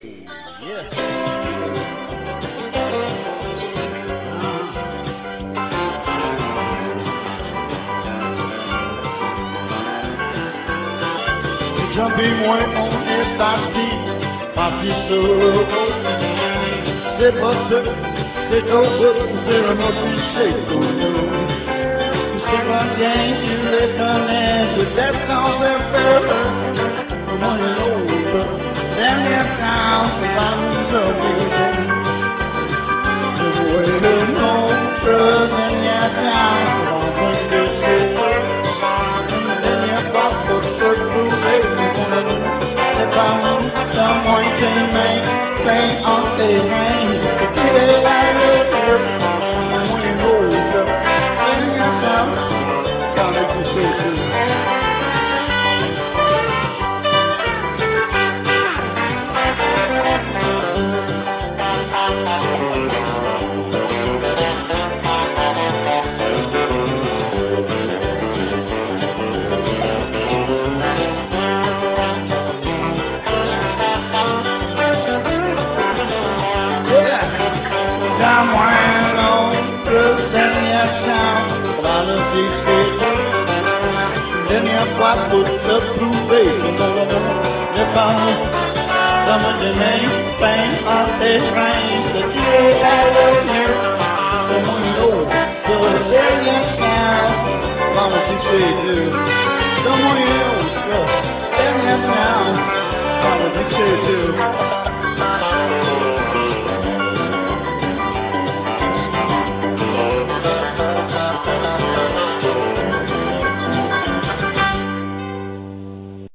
VOICI UN PEU DE CHANSON QUE JE FAIS DANS LES BARS AU QUEBEC